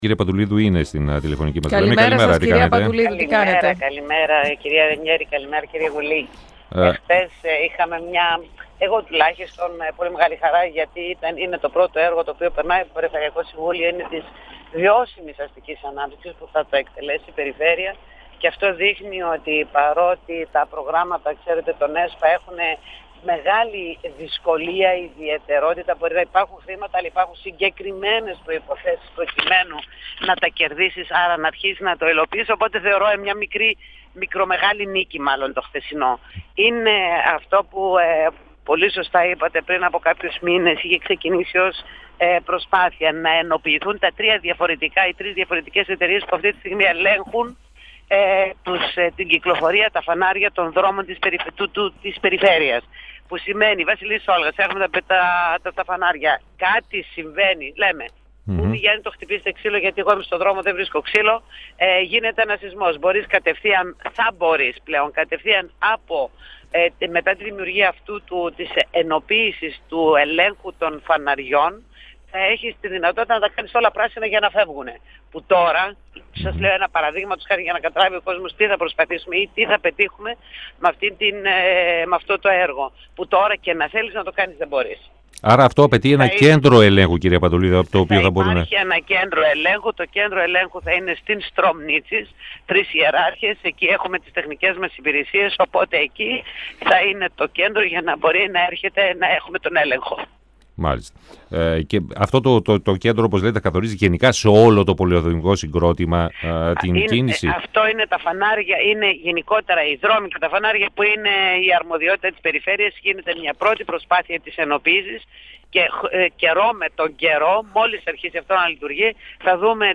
Η αντιπεριφερειάρχης Βούλα Πατουλίδου, στον 102FM του Ρ.Σ.Μ. της ΕΡΤ3